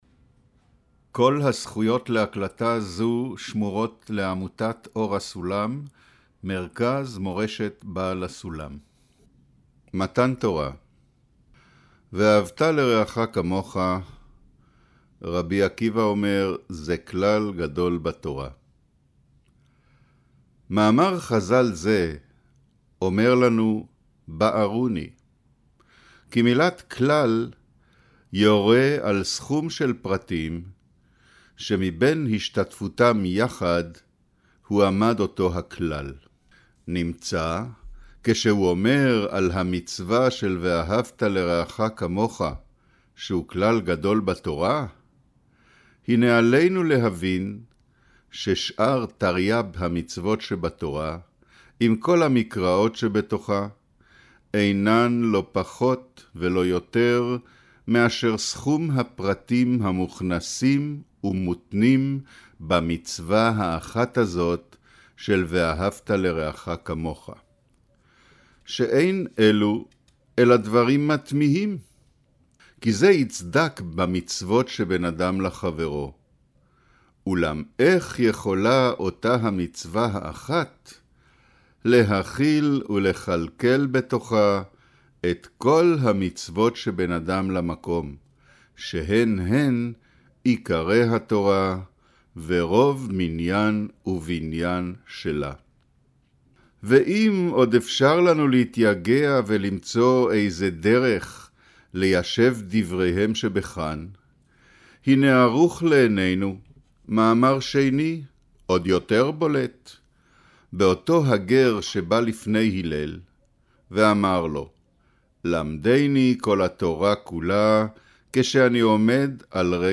אודיו - קריינות